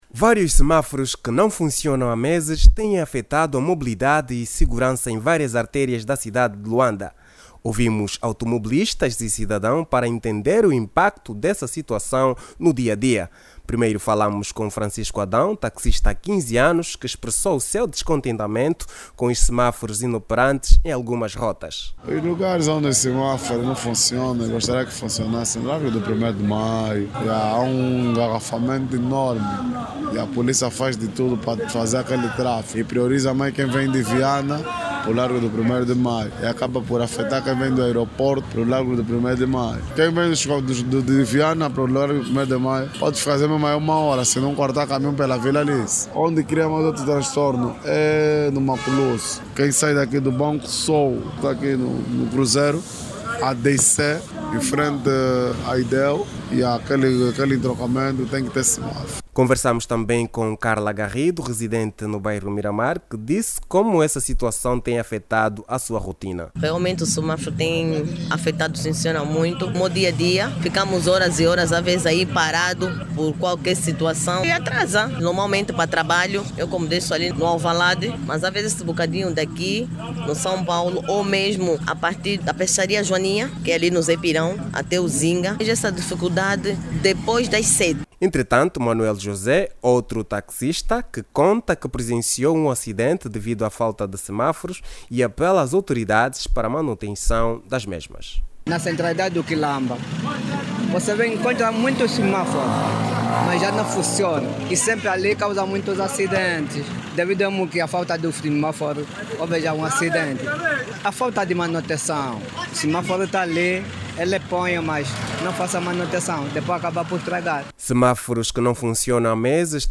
Os munícipes de Luanda mostram-se preocupados com a inoperância dos semáforos em alguns pontos. Taxistas e peões que utilizam diariamente a via pública, no centro da cidade, queixam-se dos constrangimentos por falta do funcionamento dos sinais luminosos.